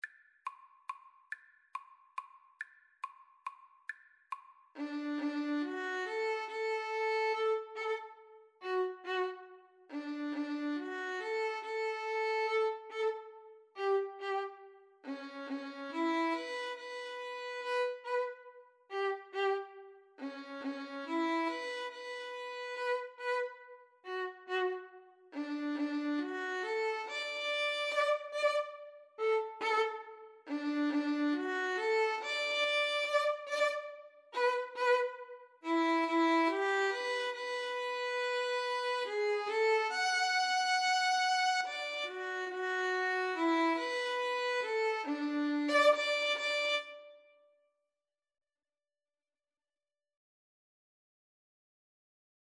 Play (or use space bar on your keyboard) Pause Music Playalong - Player 1 Accompaniment reset tempo print settings full screen
D major (Sounding Pitch) (View more D major Music for Violin Duet )
=140 Slow one in a bar
3/4 (View more 3/4 Music)
Classical (View more Classical Violin Duet Music)